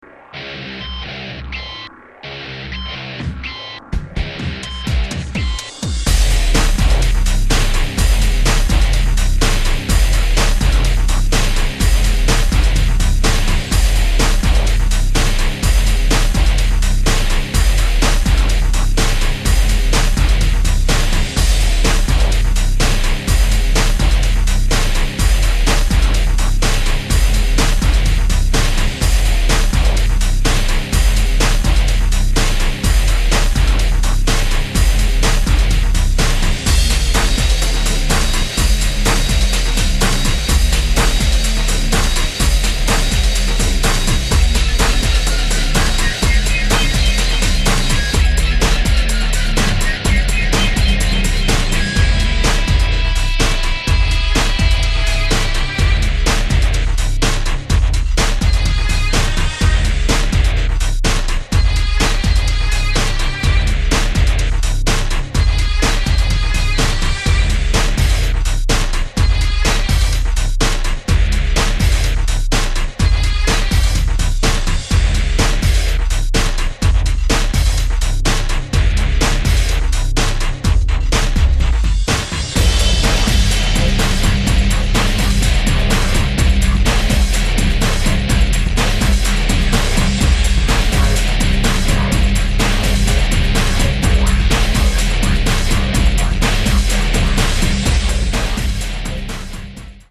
unique feel and meaning. 5 heavy guitar tracks,
Guitars played, distorted, sampled